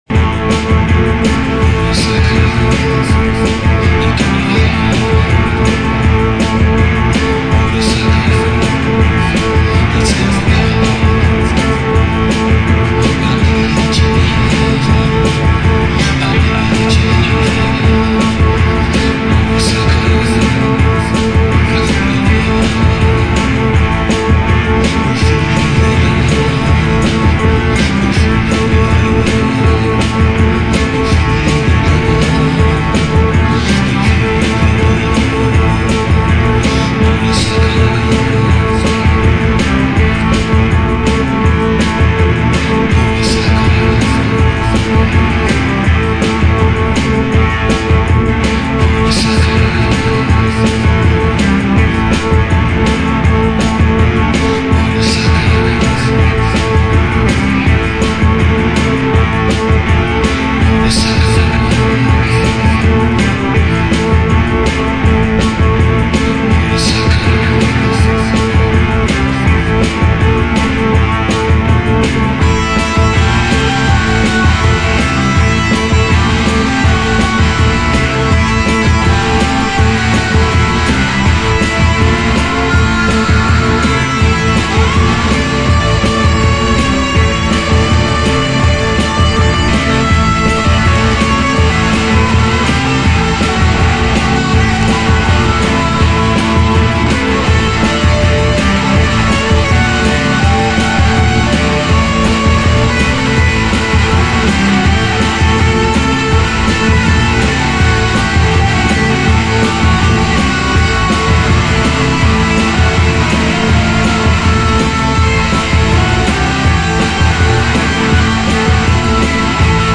Format: EP
とことんアングラな音像にもヤラれます！！